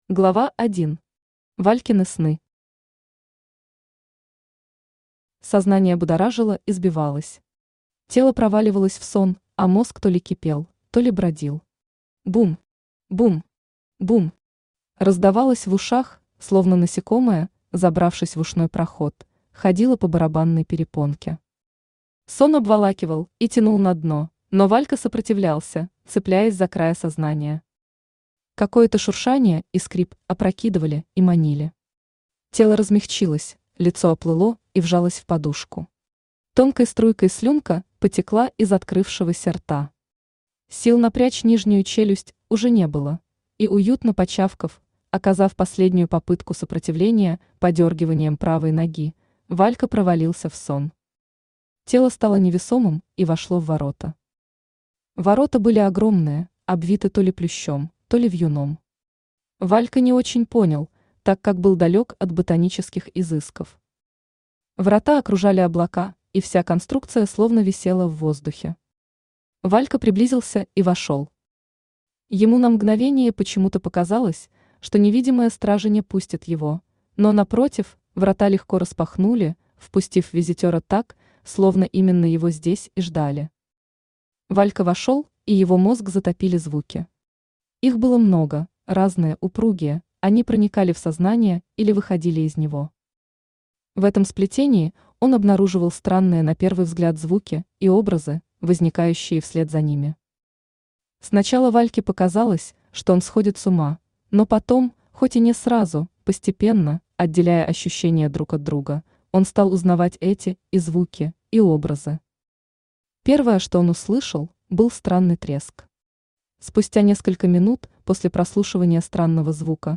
Аудиокнига Код. Путешествия по мирам бессознательного | Библиотека аудиокниг
Путешествия по мирам бессознательного Автор Аля Алев Читает аудиокнигу Авточтец ЛитРес.